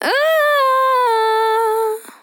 Emo ehh Sample
Categories: Vocals Tags: dry, EHH, Emo, english, female, fill, LOFI VIBES, sample